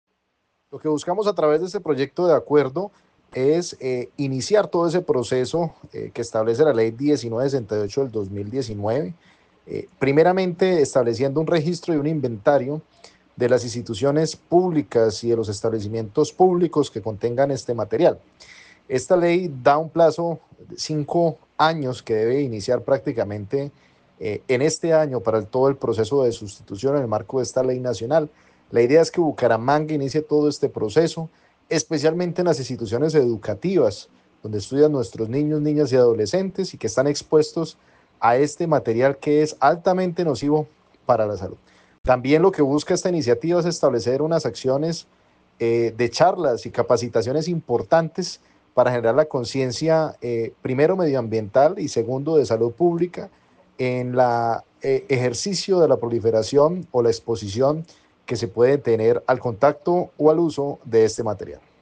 Diego Lozada, concejal de Bucaramanga